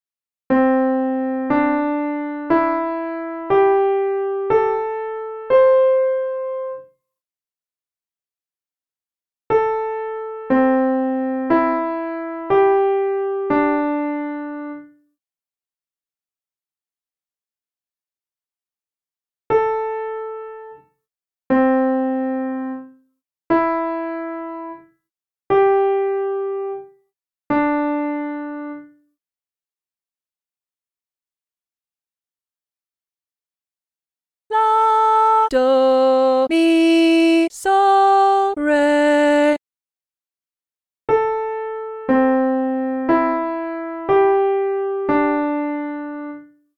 If you need a hint, listen to the hint clip which will play the melody more slowly and then reveal the solfa syllables.
To help you out, you’ll hear the full scale played before each melody:
(key: C Major)